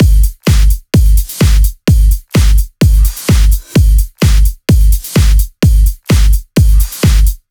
VFH2 128BPM Tron Quarter Kit 1.wav